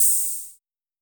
S 78_Ohh.wav